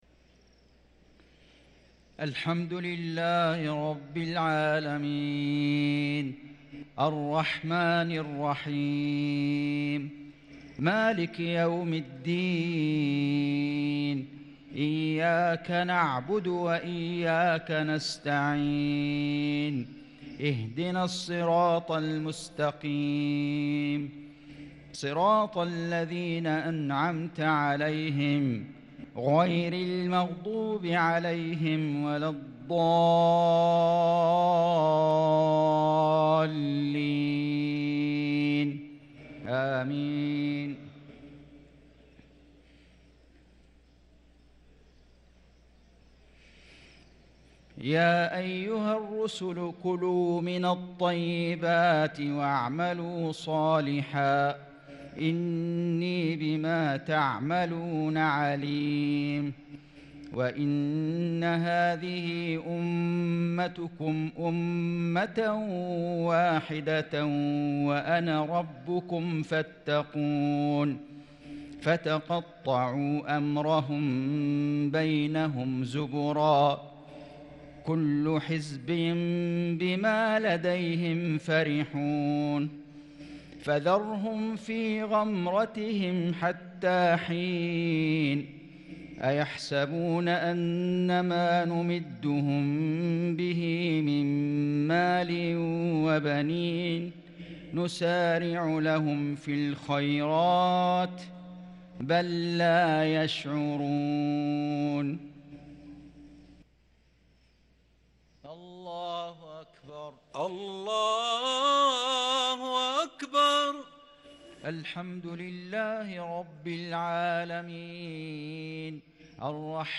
مغرب ٤ رمضان ١٤٤٣هـ من سورة المؤمنون | Maghrib prayer from Surah al-Mu'minun 5-4-2022 > 1443 🕋 > الفروض - تلاوات الحرمين